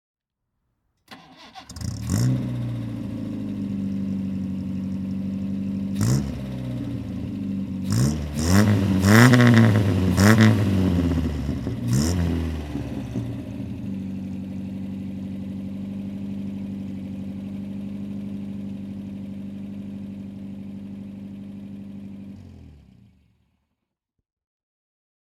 Moorgan Plus 4 (1956) - Starten und Leerlauf
Morgan_Plus_4_1956.mp3